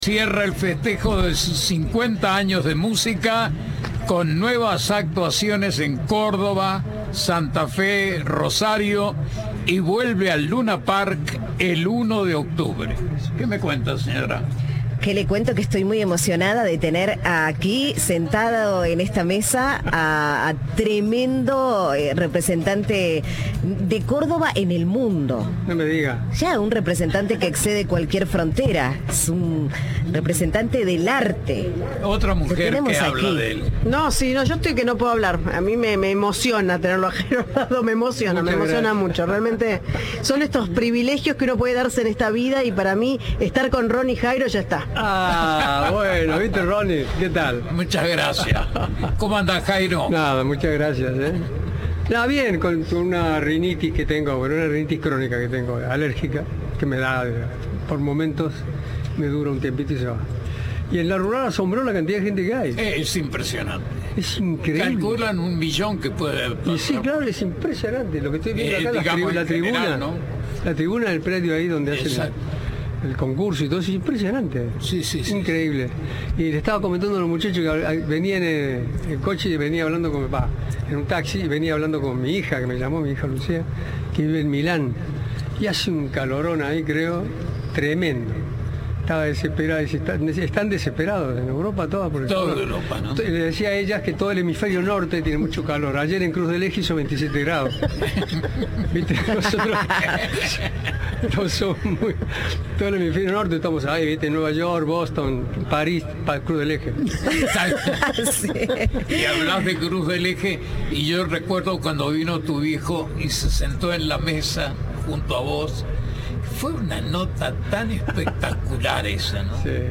Jairo: hitos de su carrera y una emotiva canción a su esposa
El cantante de folclore recordó sus épocas en Europa y la forma de componer que tenía con Daniel Salzano. Habló de sus hijos y de su próximo disco de dúos.